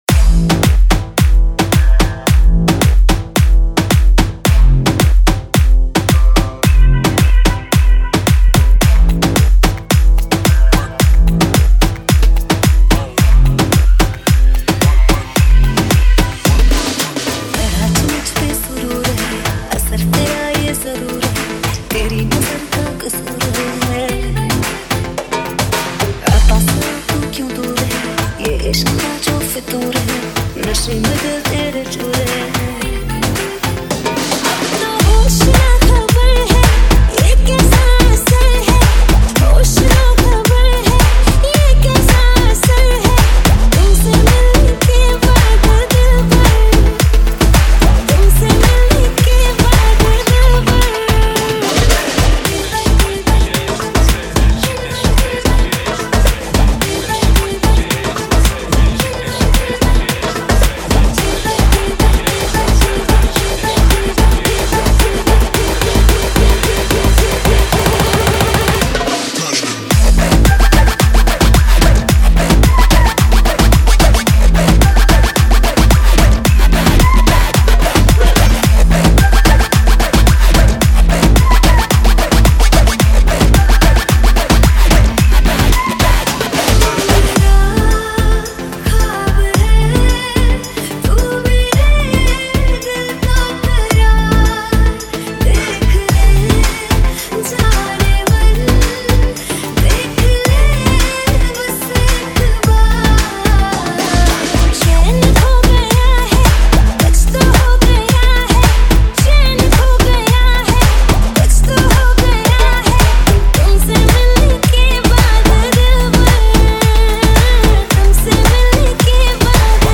DJ Remix Mp3 Songs > Latest Single Dj Mixes